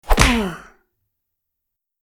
Tiếng Cú Đấm và tiếng ừc….
Thể loại: Đánh nhau, vũ khí
tieng-cu-dam-va-tieng-uc-www_tiengdong_com.mp3